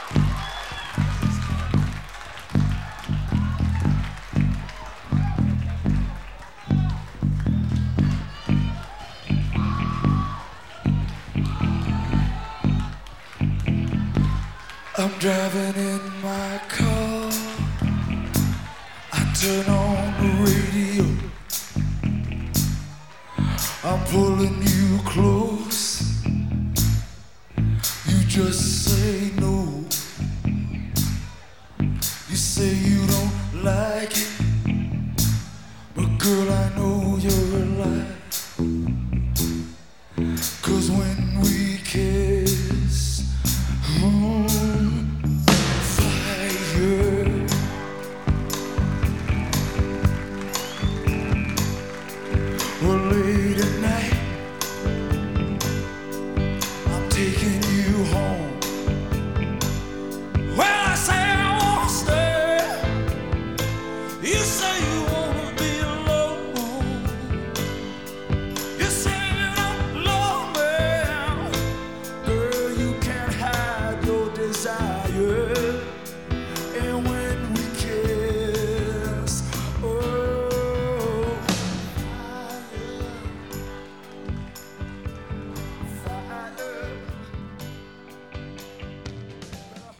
with its heavy use of the bass and his sweaty vocals